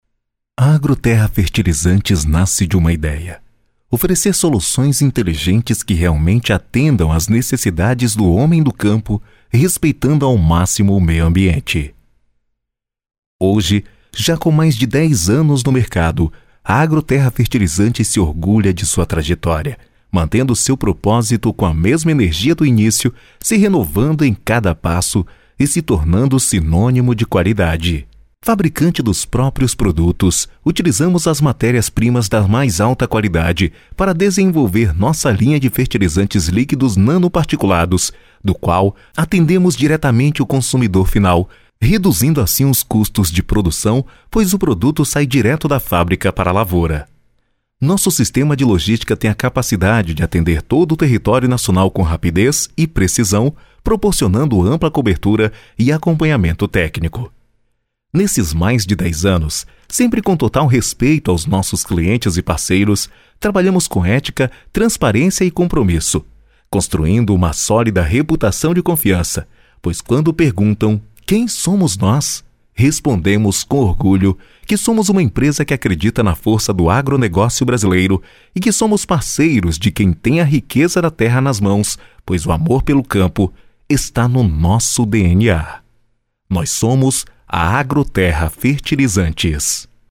Tom firme, imponente, cadenciado mas com bom andamento.